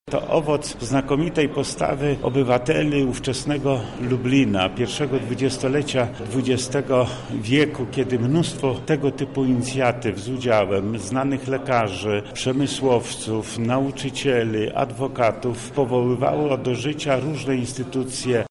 Biblioteka świadczy o ambicjach czytelników – dodaje wojewoda lubelski Lech Sprawka: